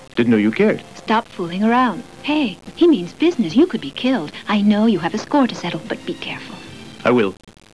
It's a remarkable, rushed piece of breathless voice acting, the best
Speed Racer delivery I've heard outside of that fine program.